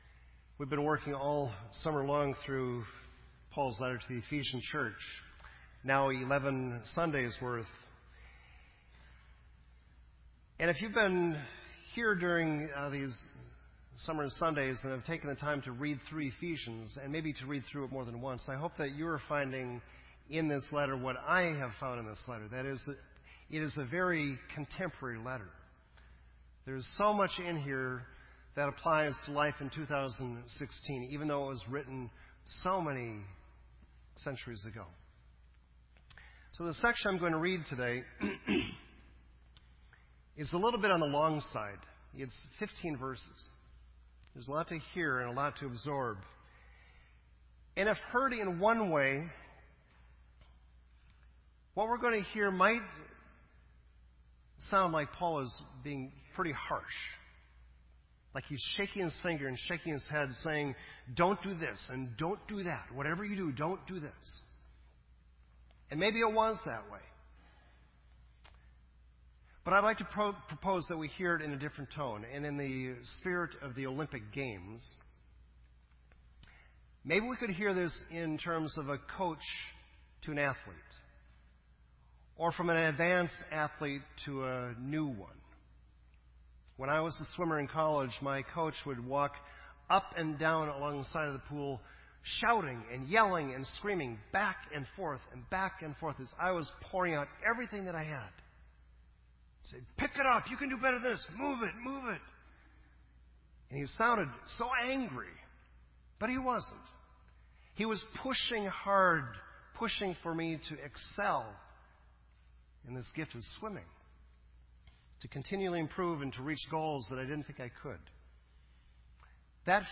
This entry was posted in Sermon Audio on August 15